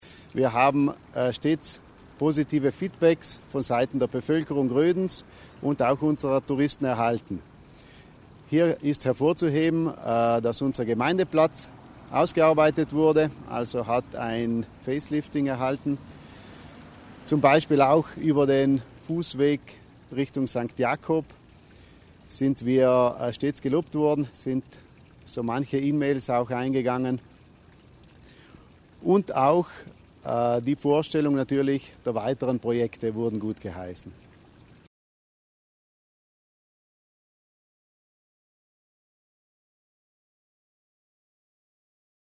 Bürgermeister Hofer über den Erfolg des Projekts